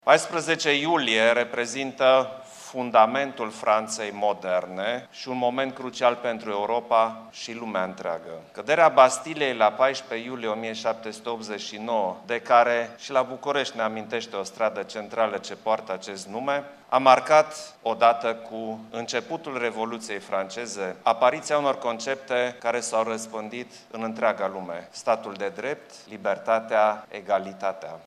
Prietenia româno-franceză este puternică – a declarat președintele Klaus Iohannis, la recepția organizată de Ziua Franței.  Șeful statului a vorbit despre relațiile economice, francofonie, dar și despre perioada pandemiei, în care românii și francezii au arătat că solidaritatea există.
14iul-20-Iohannis-Caderea-Bastiliei-.mp3